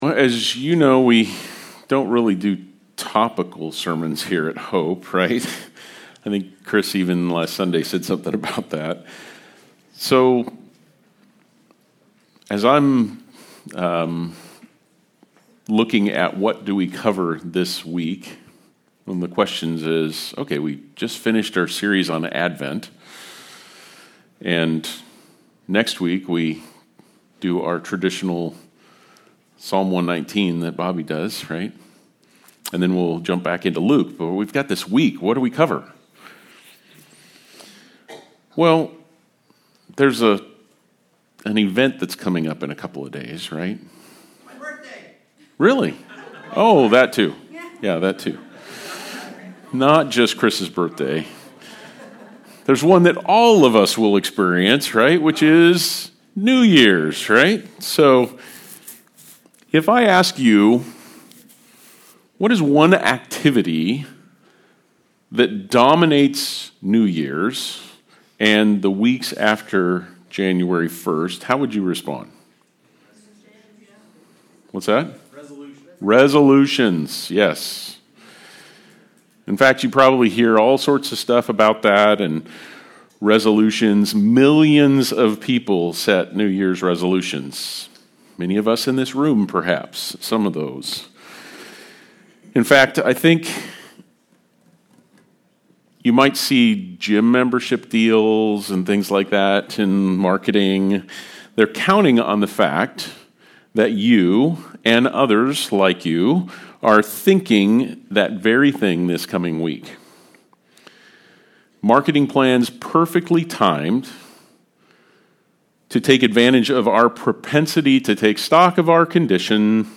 Passage: James 4:13-17 Service Type: Sunday Service